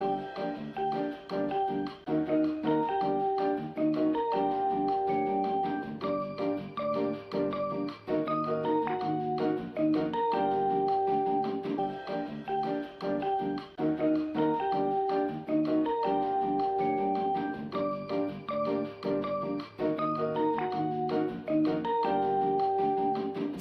elevator.mp3